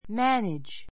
mǽnidʒ